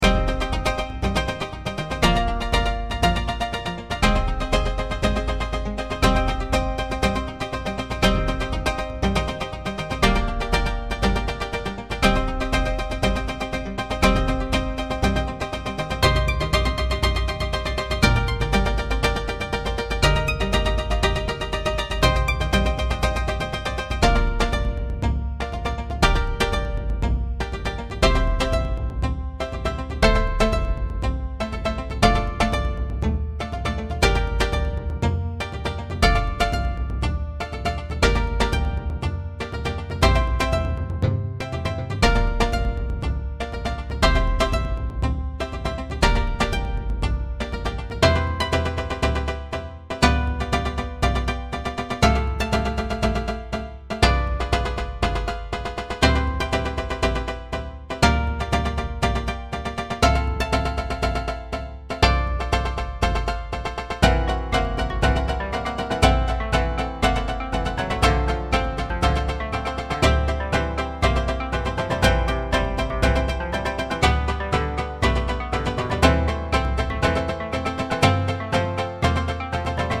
琴、三味線、太鼓 ※和楽器